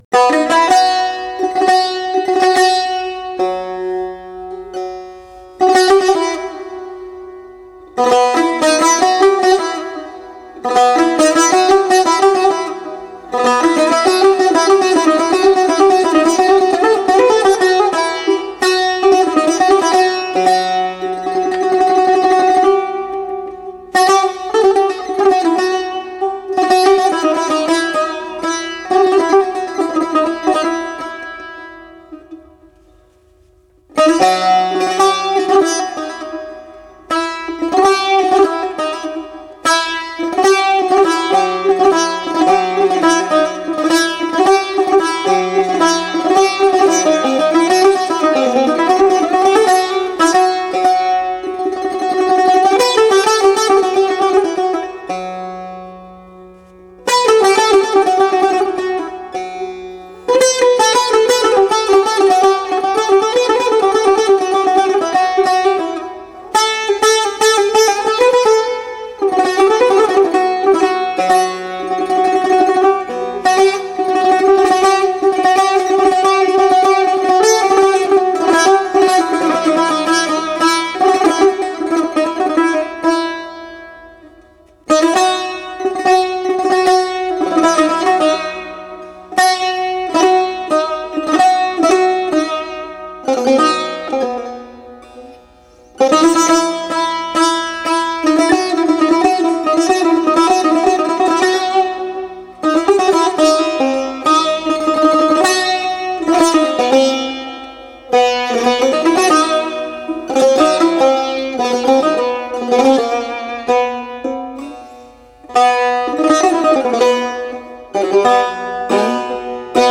Taknavazie Tar